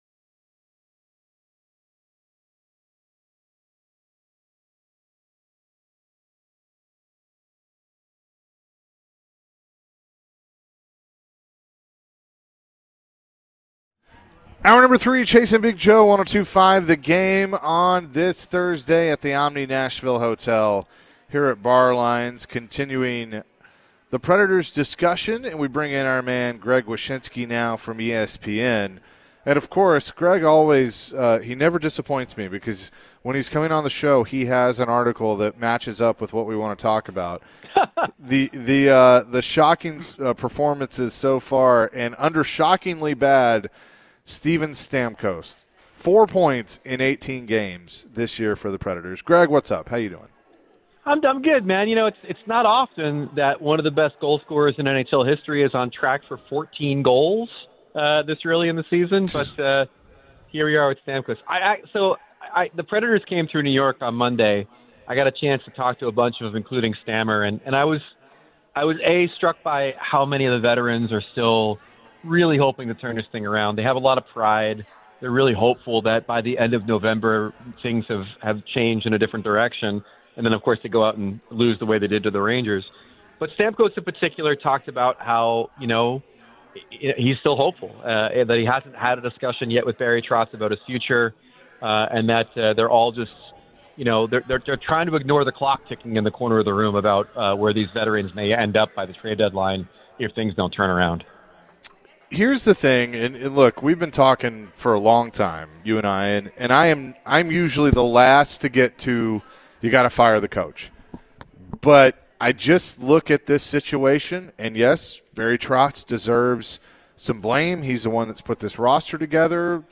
The guys chatted with ESPN NHL Analyst Greg Wyshynski. Greg shared his thoughts on the Preds' recent form and the upcoming Global Series. Greg also shared his thoughts on the Preds roster rumors.